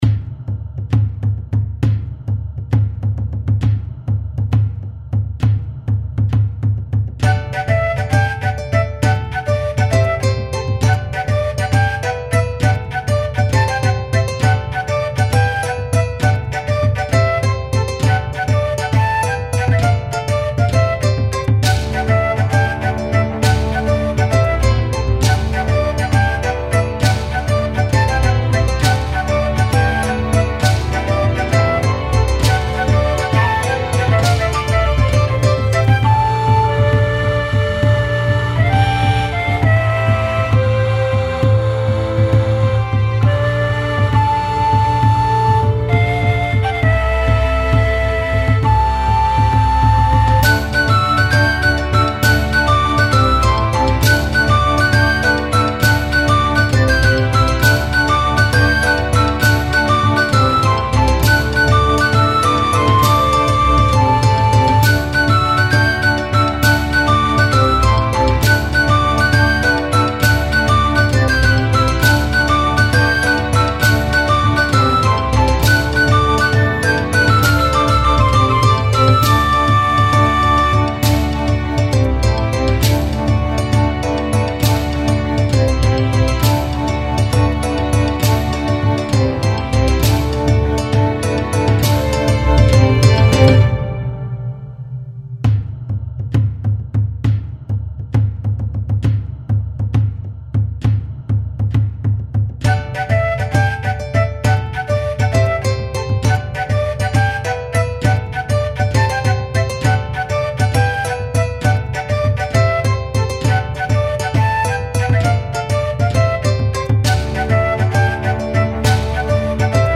BGM
ロング明るい民族